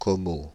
Commeaux (French pronunciation: [kɔmo]
Fr-Commeaux.ogg.mp3